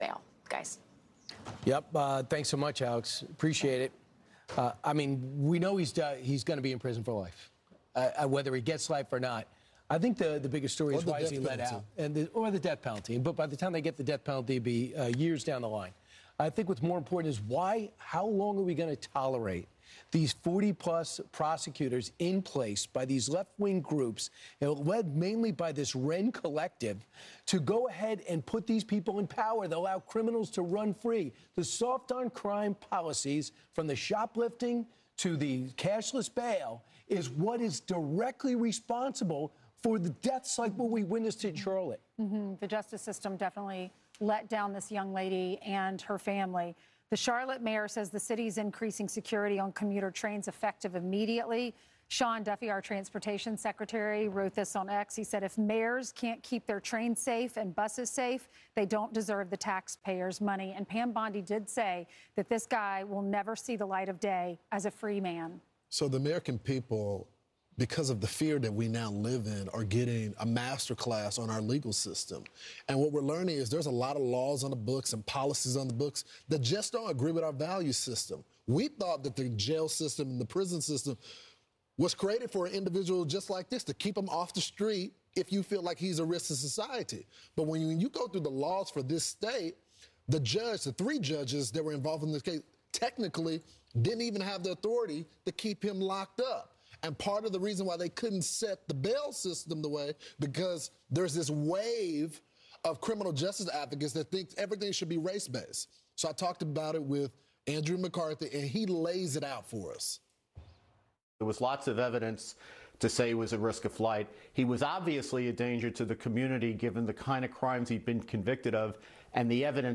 fox-news-lethal-injections-interview-brian-kilmeade-ainsley-earhardt-lawrence-jones-full